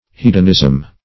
Hedonism \Hed"on*ism\, n.